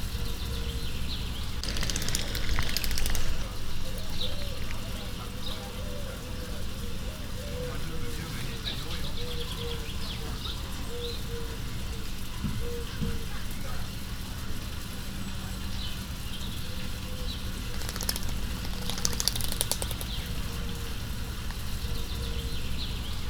Directory Listing of //allathangok/veszpremizoo2013_premium/feketegolya/